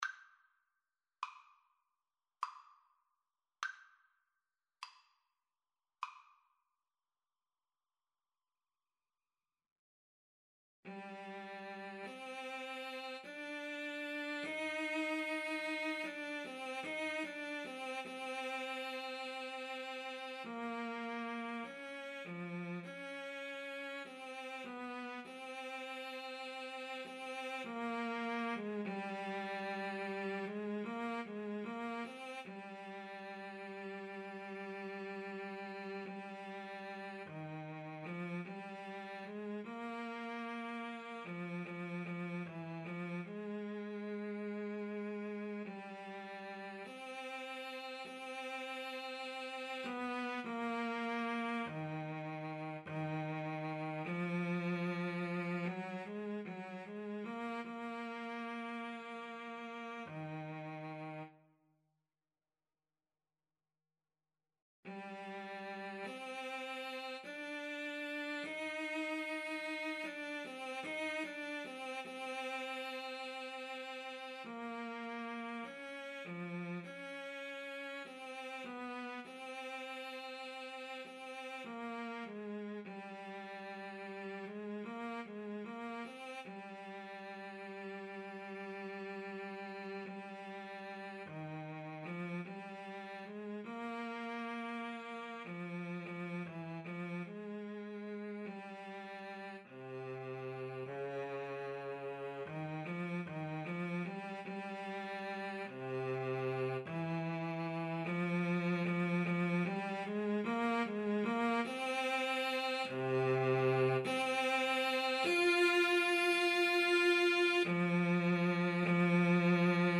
Play (or use space bar on your keyboard) Pause Music Playalong - Player 1 Accompaniment reset tempo print settings full screen
Eb major (Sounding Pitch) (View more Eb major Music for Cello Duet )
Andantino = 50 (View more music marked Andantino)
Classical (View more Classical Cello Duet Music)